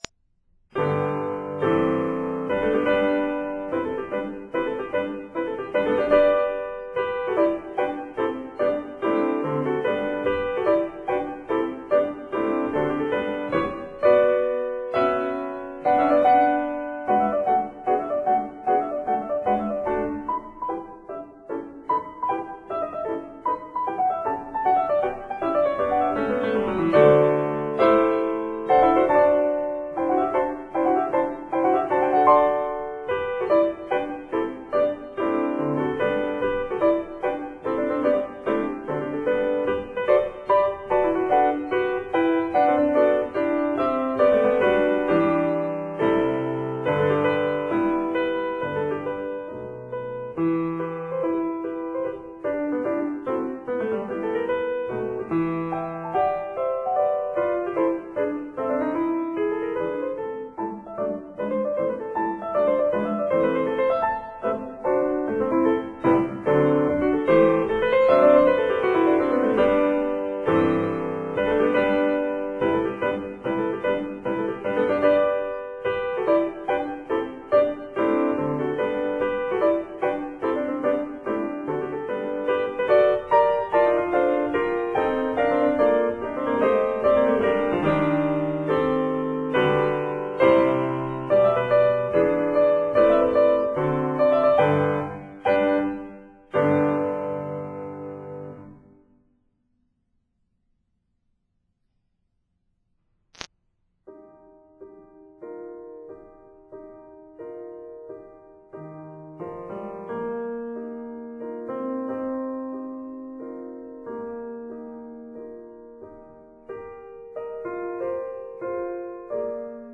arr. for two pianos